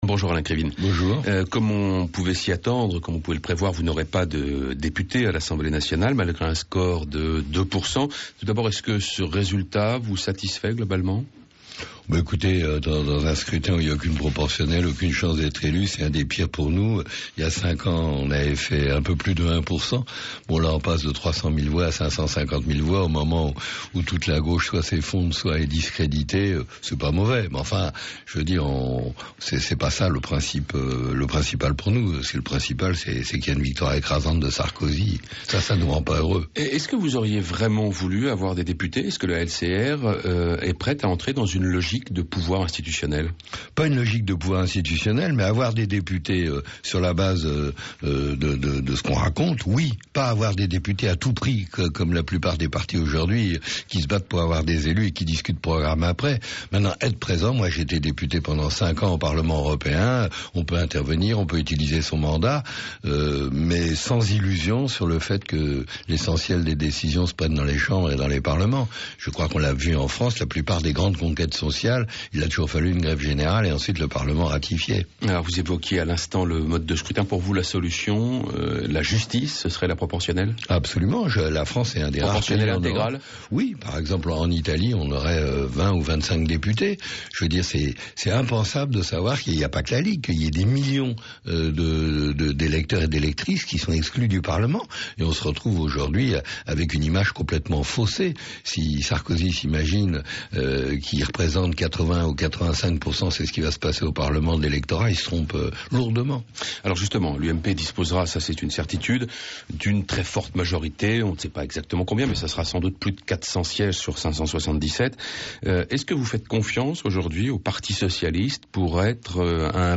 Invité du matin
« Aujourd’hui le PS donne un spectacle absolument démentiel, lamentable devant l’opinion » Alain Krivine, porte parole de la Ligue communiste révolutionnaire (LCR)